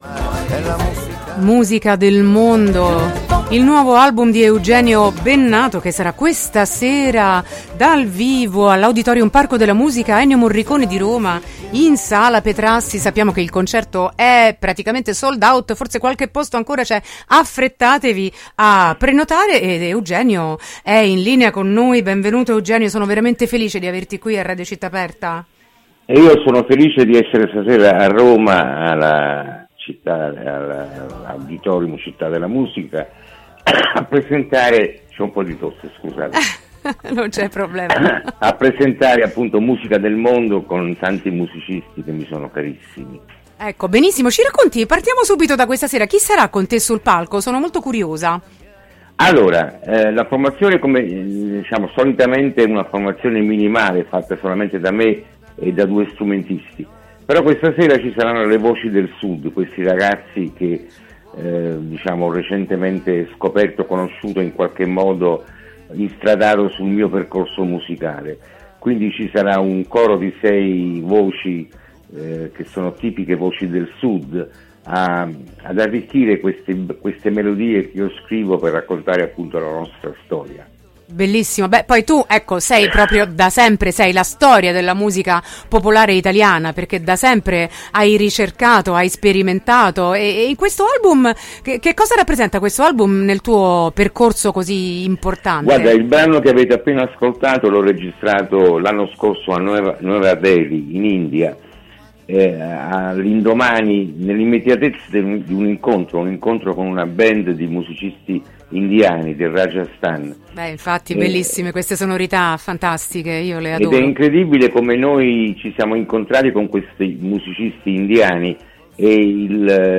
“Musica del mondo” arriva a Roma: Intervista a Eugenio Bennato | Radio Città Aperta
Intervista-eugenio-bennato-28-3-25.mp3